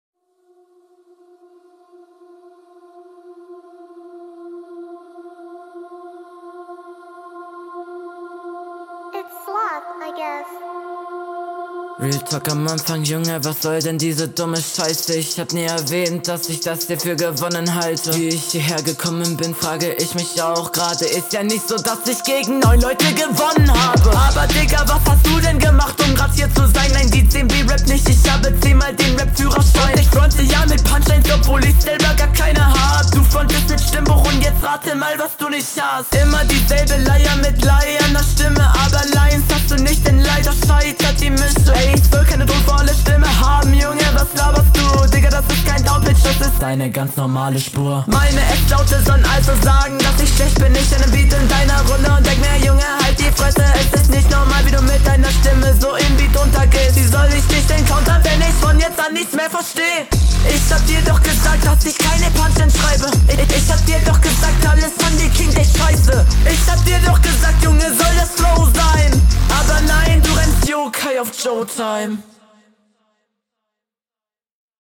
Flow: Der Flow ist ganz nice.
Flow ist im allgemeinen wieder ganz gut, aber nichts überragendes.
DeEsser bisschen zu stark eingestellt, das klingt leider so als würdest du lispeln. stört mich …